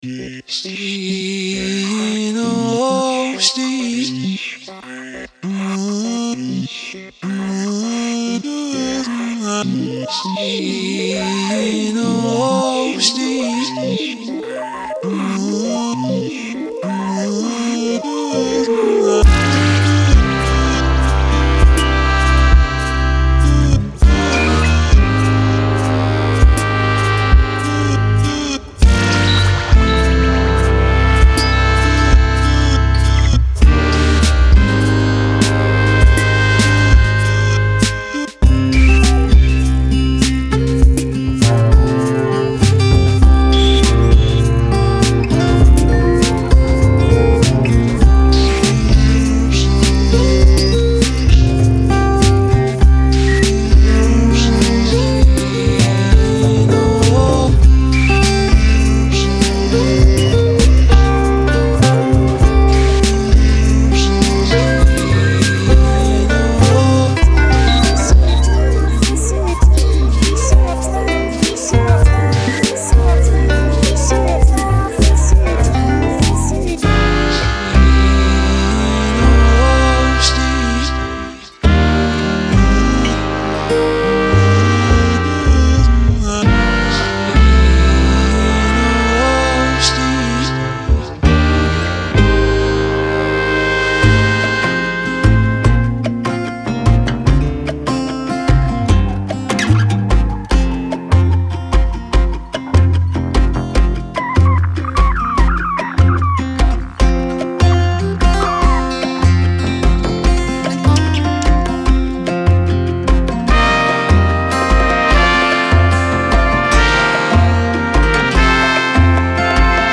Genre:Folk Music
さらに特典として、本コレクションのインストゥルメンタルと美しく調和する女性ボーカルのセットも収録されています。
デモサウンドはコチラ↓